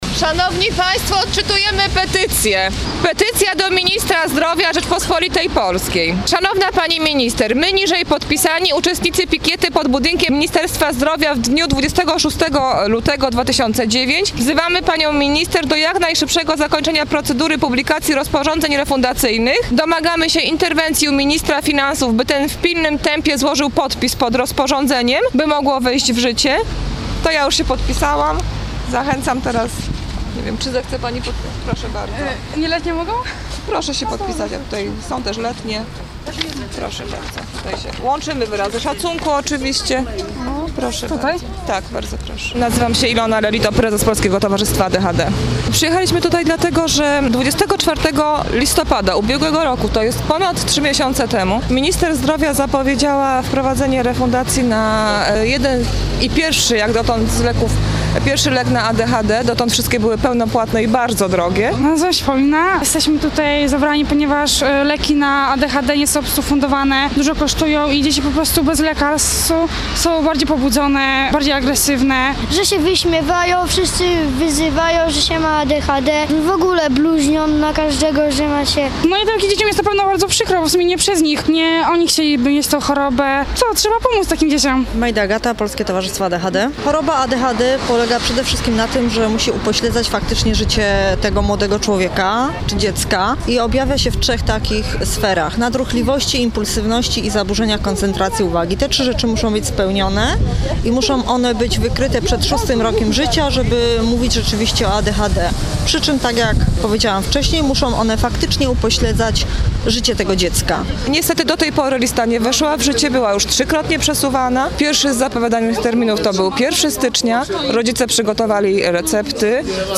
Pikieta pod Ministerstwem Zdrowia
26 lutego 2009 r. Warszawa, ul. Miodowa 15
nagranie_pikieta.mp3